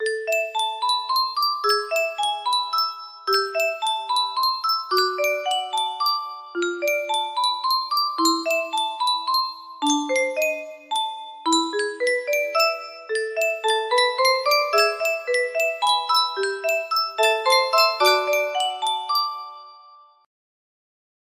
shorter for my 150note music box